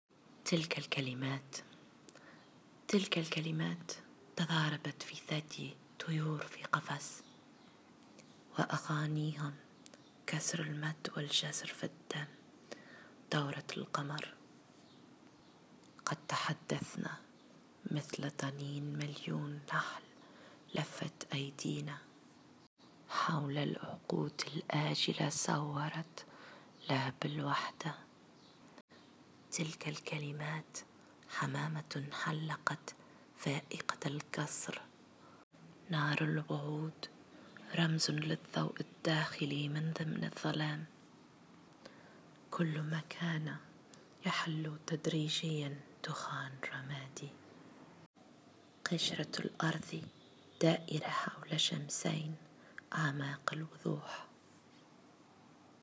قراءة القصيدة: